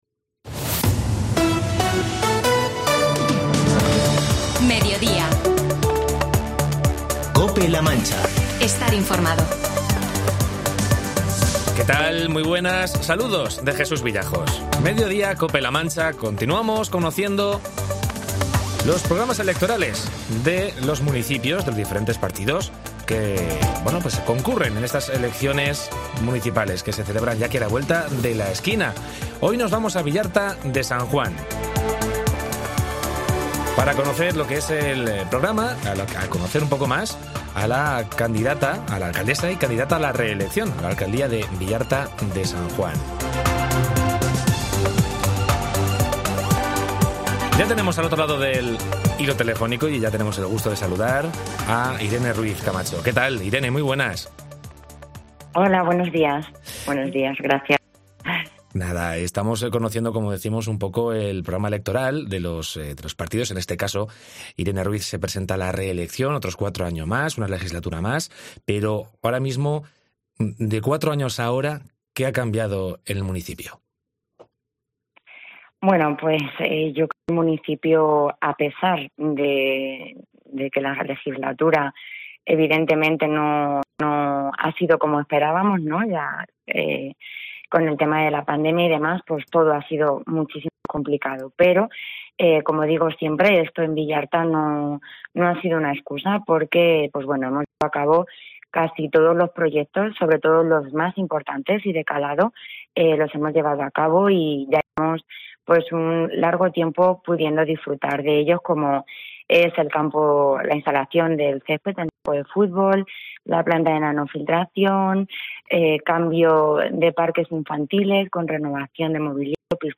Entrevista a Irene Ruíz, alcaldesa y candidata a la reelección en el Ayuntamiento de Villarta de San Juan